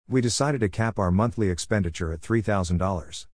確認テスト(ディクテーション)
以下の例文で弱化した単語を聴き取る練習をしましょう。